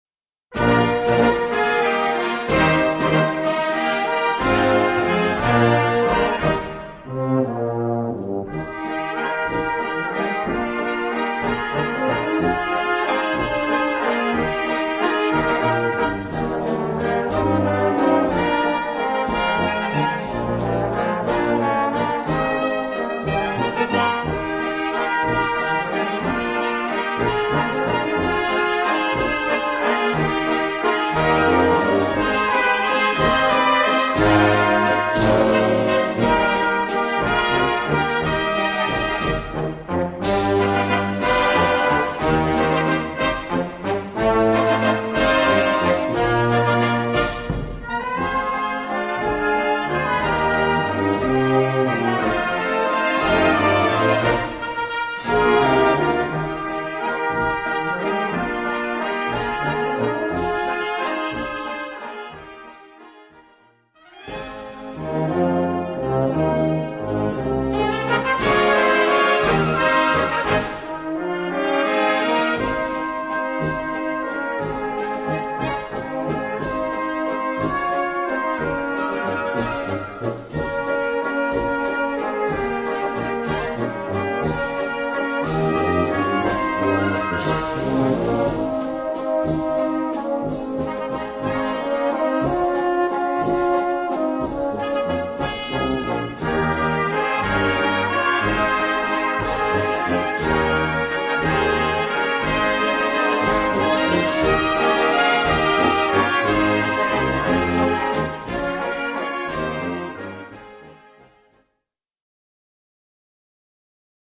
Gattung: Walzer
Besetzung: Blasorchester